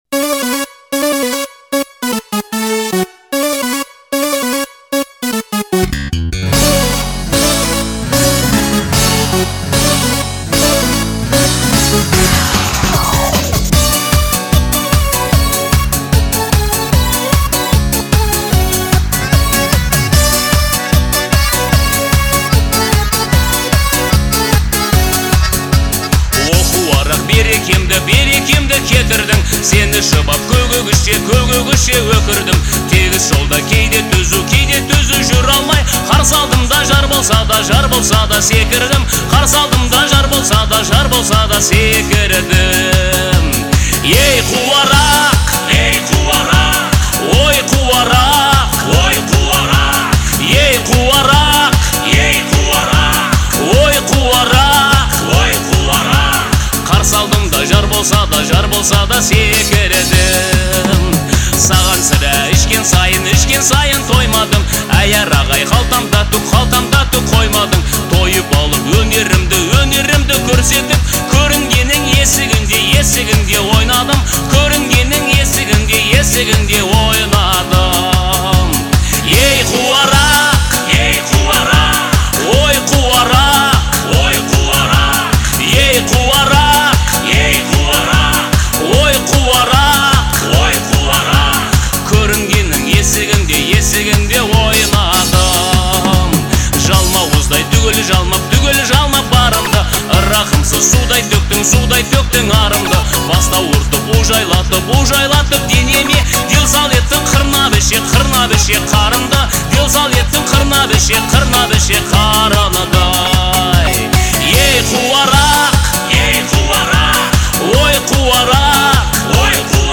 а задорные ритмы заставляют двигаться в такт музыке.